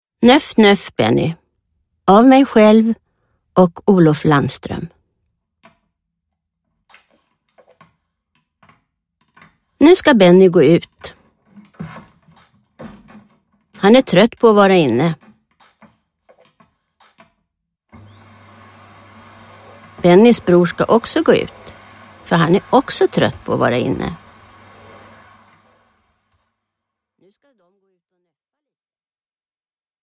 Nöff nöff Benny – Ljudbok – Laddas ner